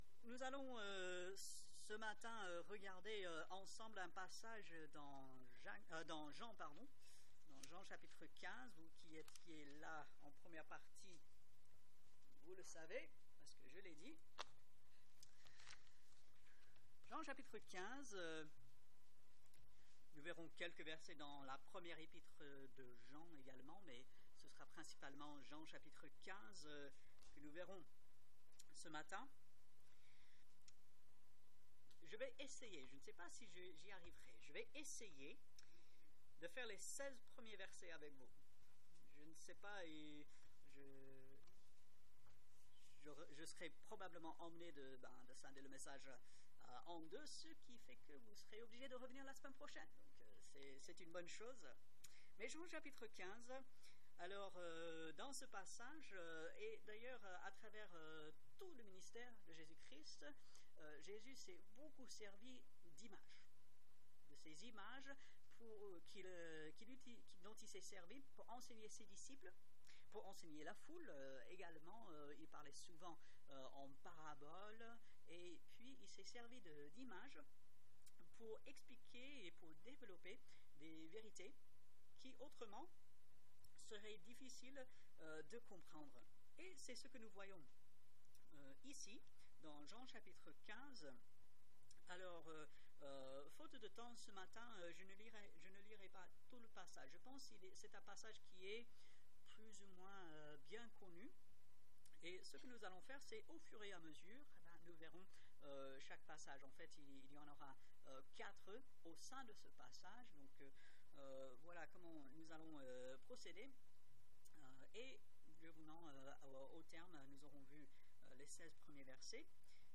Ici nous vous proposons l'écoute des prédications qui sont apportées le dimanche matin.